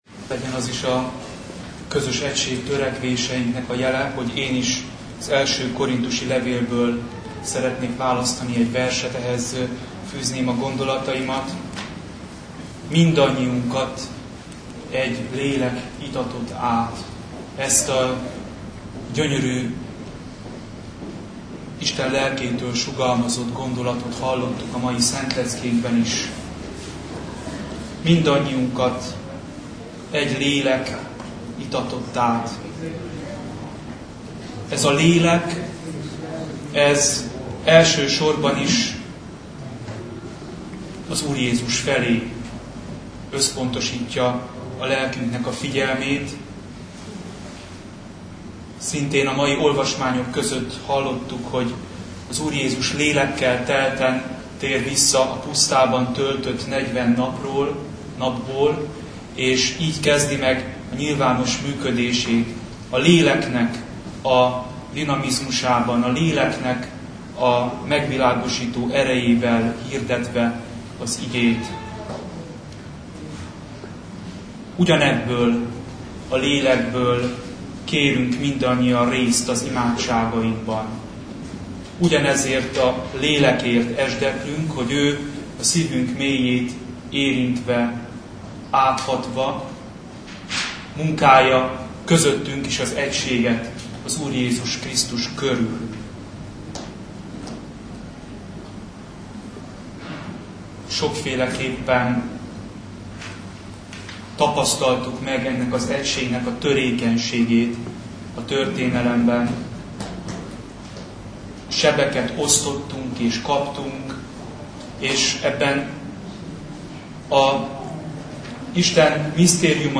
Ökumenikus
Istentisztelet (mp3)